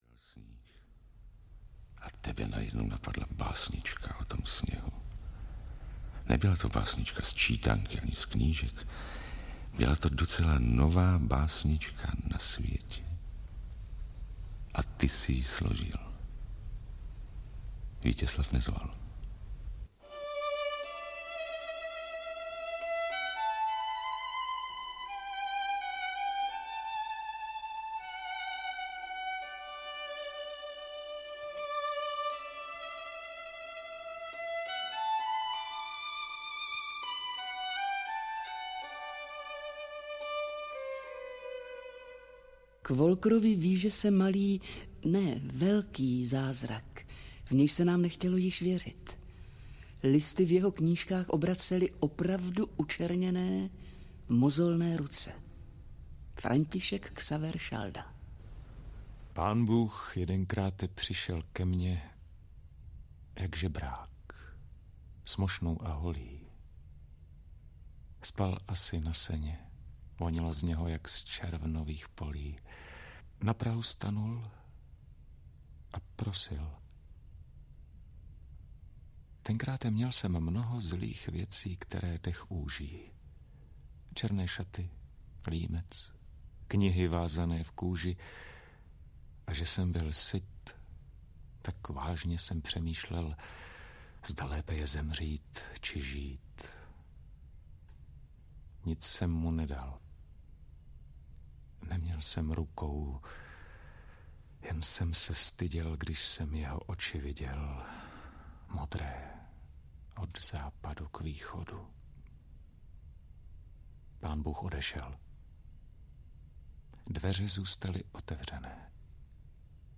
Zahrej, chraplavý Aristone. Kompozice - portrét na téma Wolker audiokniha
Ve spolupráci s divadlem Viola nahrál Supraphon v únoru 1989 kompozici - portrét na téma Wolker "Zahraj, chraplavý Aristone" .K vydání tohoto portrétu básníka, jenž miloval svět a pro spravedlnost jeho šel se bít.Dřív než moh' srdce k boji vytasit, zemřel – mlád dvacet čtyři let.Plánované LP album mělo být vydáno k 90. výročí jeho narození, to se již ale v překotném začátku roku 1990 a ani v pozdějších "kulatých" letech nestalo...A tak dnes, 30. let poté, vychází kompozice básní, korespondence a myšlenek Jiřího Wolkra či myšlenek a výroků slavných osobností o Jiřím Wolkrovi k 120. výročí jeho narození poprvé.